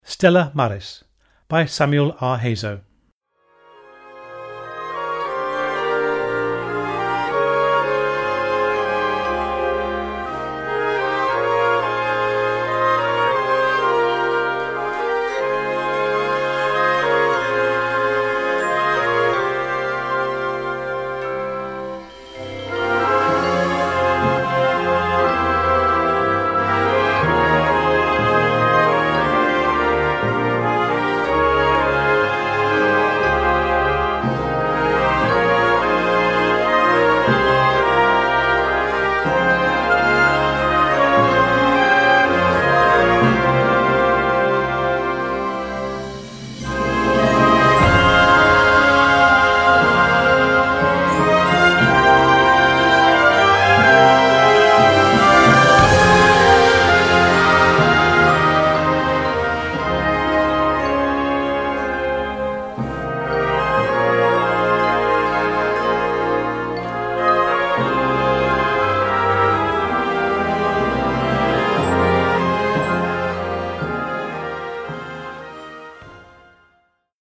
Category: SLOW TUNES - Grade 4.0